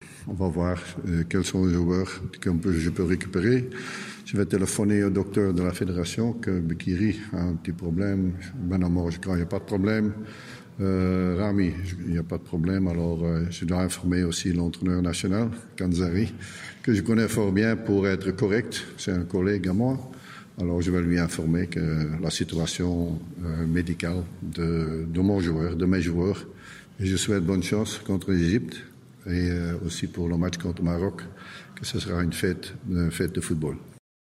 أكد مدرب النجم الساحلي جورج ليكانز خلال الندوة الصحفية التي تلت لقاء النجم الساحلي و نجم المتلوي (1-0) أن الحارس مكرم البديري قد تعرض لإصابة و قد لا يكون جاهزا للمشاركة في مقابلة المنتخب الوطني التونسي أمام نظيره المصري المبرمجة يوم 16 نوفمبر 2018 في ملعب برج العرب بالإسكندرية في اطار الجولة الخامسة من تصفيات كاس افريقيا 2019.
جورج ليكنز : مدرب النجم الساحلي